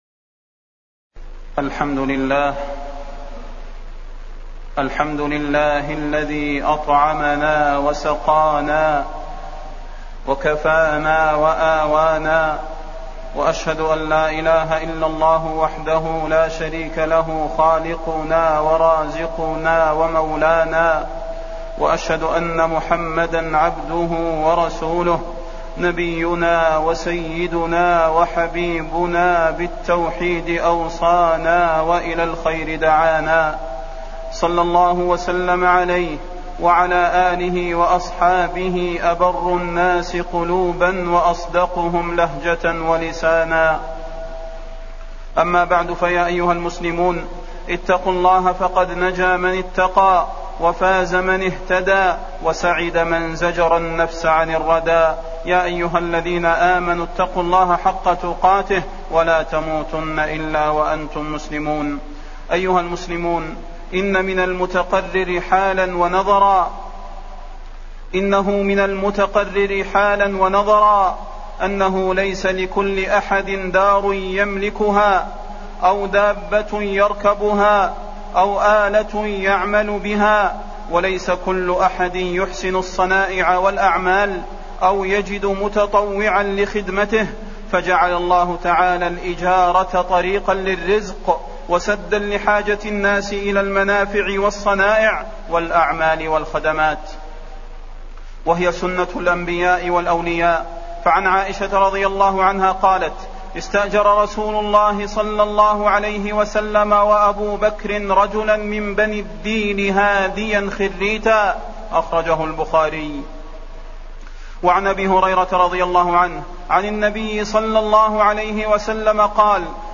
تاريخ النشر ١٩ ربيع الثاني ١٤٢٩ هـ المكان: المسجد النبوي الشيخ: فضيلة الشيخ د. صلاح بن محمد البدير فضيلة الشيخ د. صلاح بن محمد البدير حقوق المستأجر والأجير